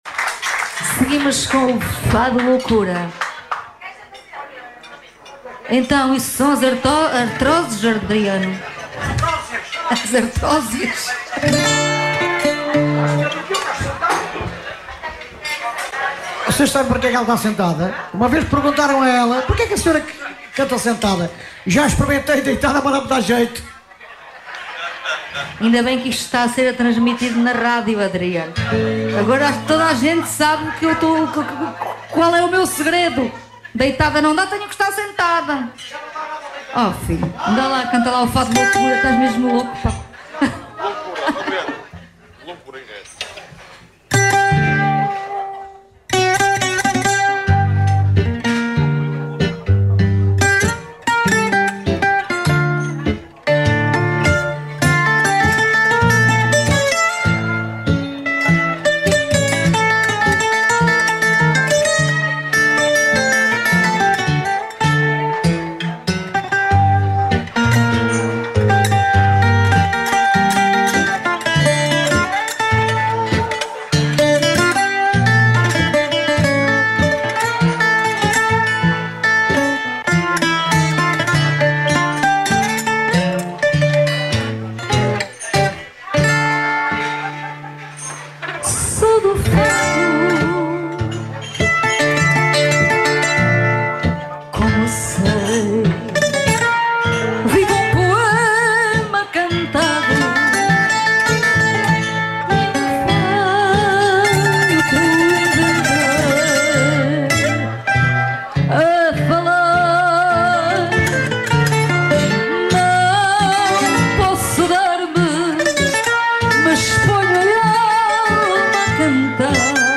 “Só Fado” -Todas as Sextas entre as 21 e as 23 horas, a Rádio Alfa transforma-se numa verdadeira casa de fado.